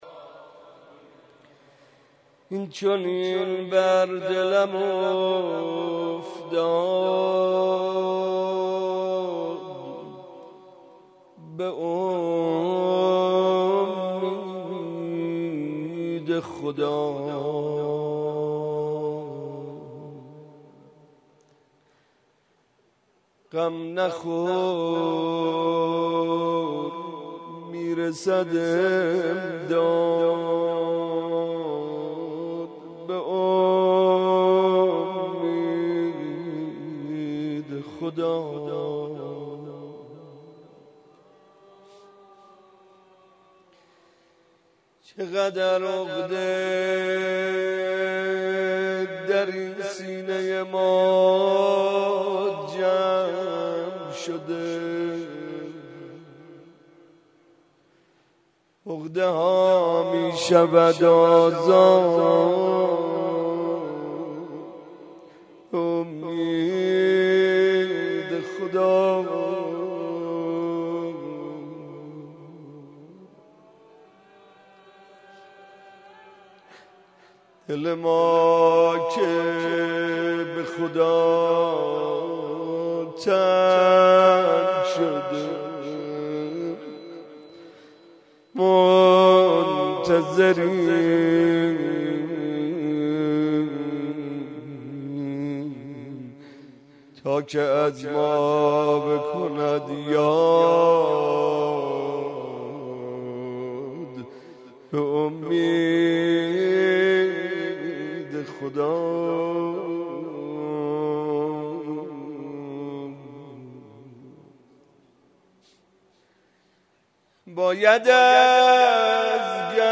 مناسبت : شب بیست و چهارم رمضان
قالب : مناجات